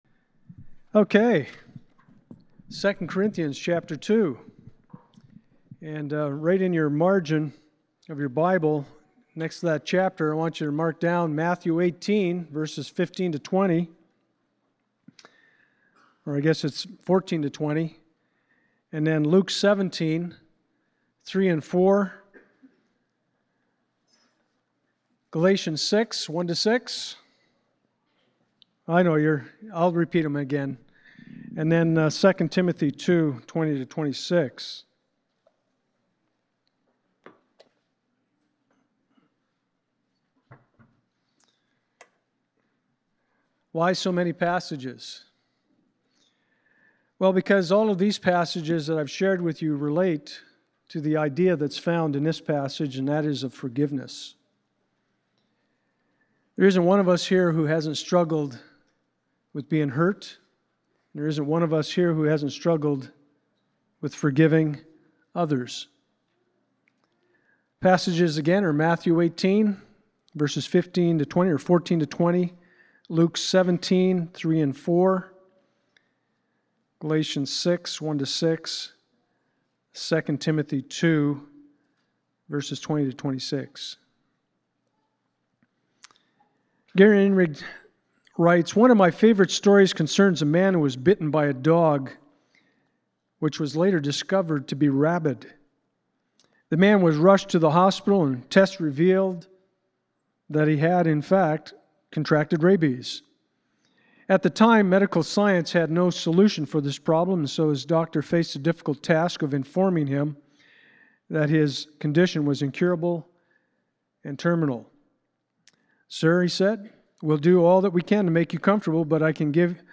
Matthew 18:15-20 Service Type: Sunday Morning « Saul’s Call We All Want Justice…